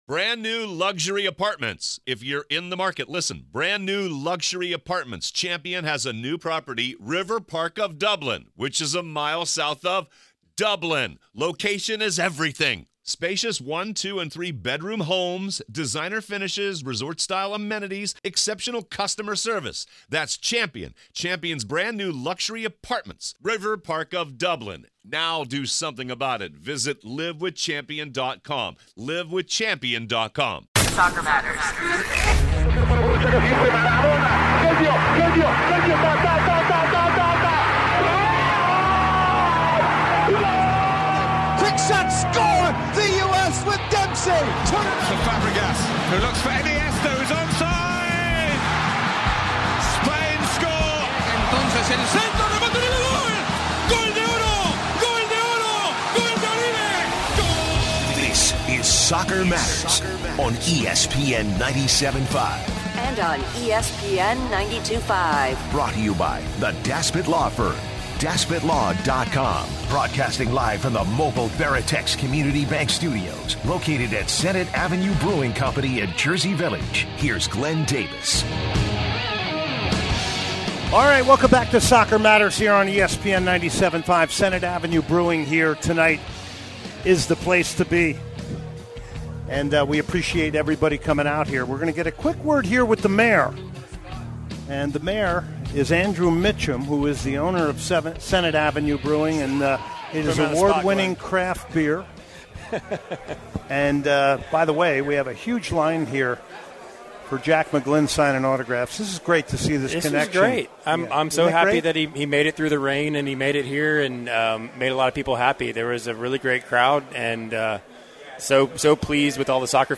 supporters groups from Houston discuss the exciting start to the Premier League which begins this Friday